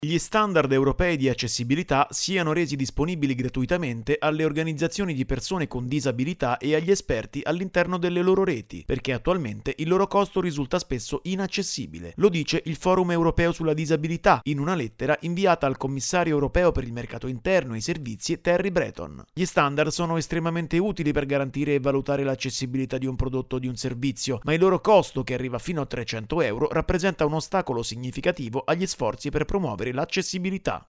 Il Forum Europeo sulla Disabilità chiede che siano garantite maggiori condizioni di accessibilità. Il servizio